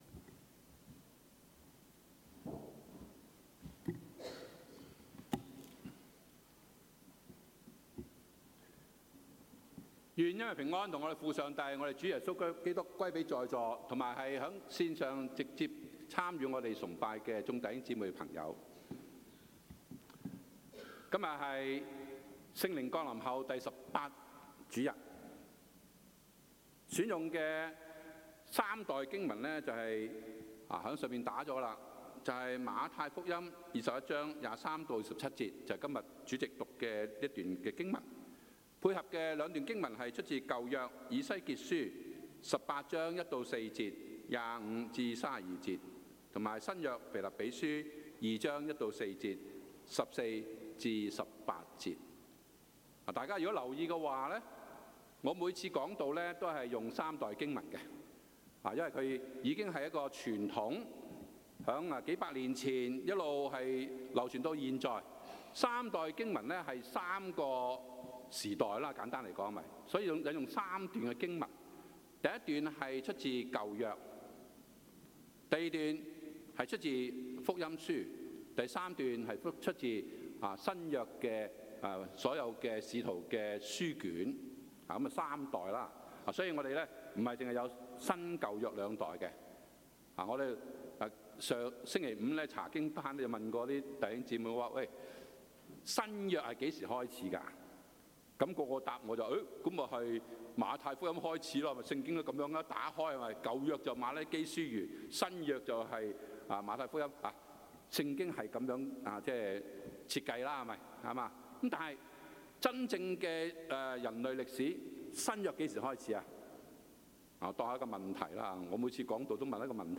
English Houseparty 2023
Q and A